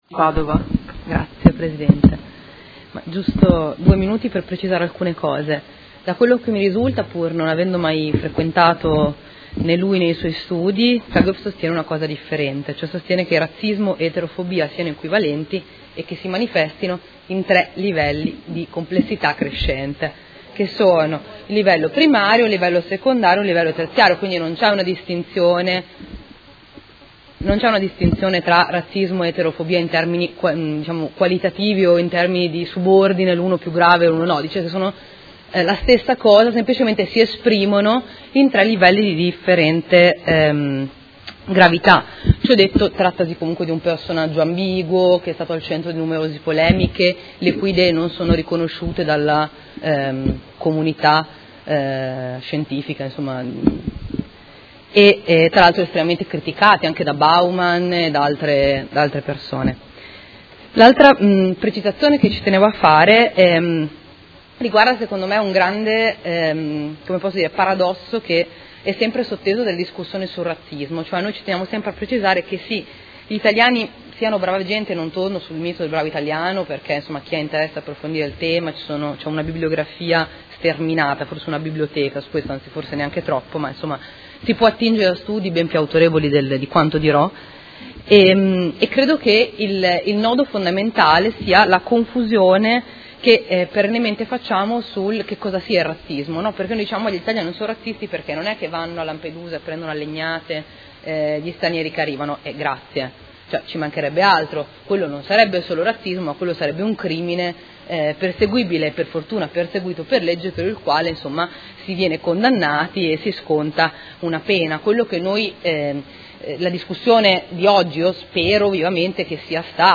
Seduta dell’8/11/2018.